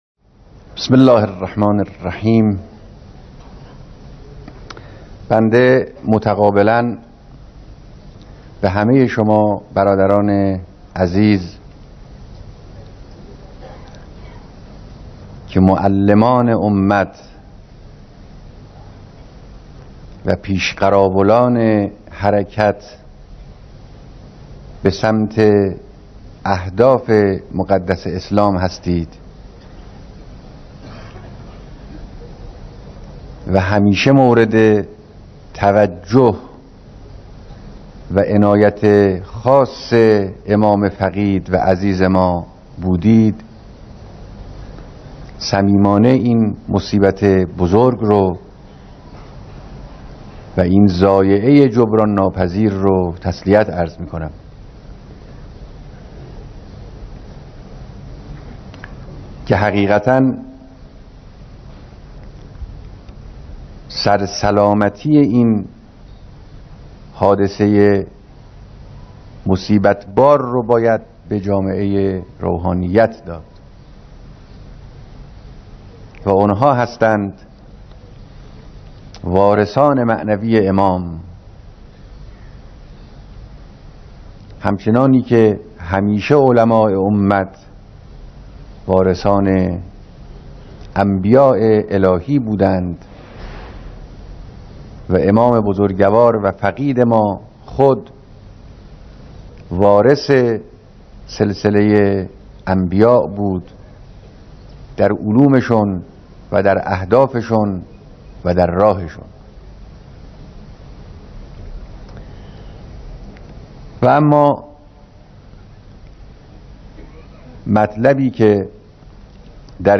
بیانات در مراسم بیعت طلاب و روحانیون